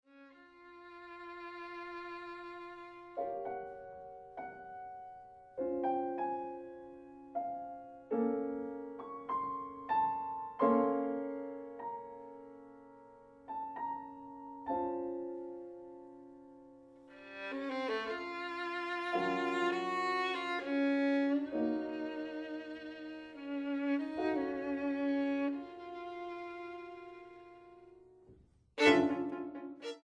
Sonata para violín y piano (2012)